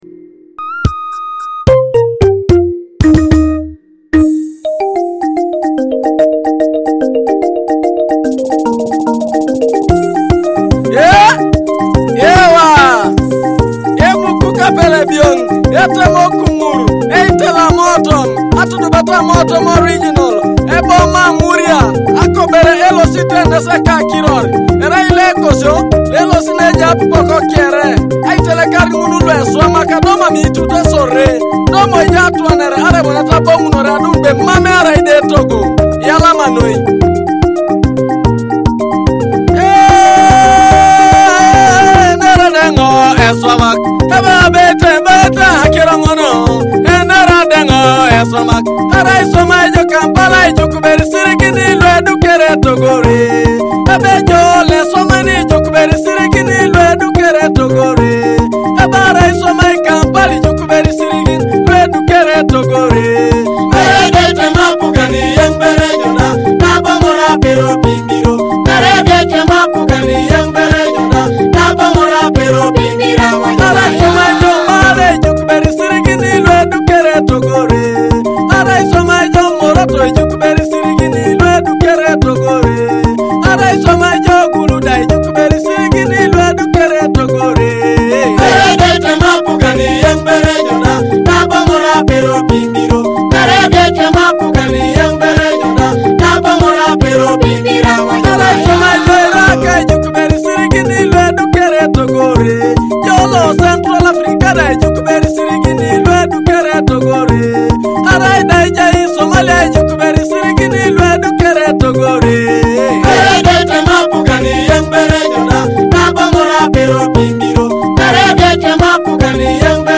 featuring joyful Ateso cultural and traditional rhythms
the melodic sounds of the Akogo (thumb piano)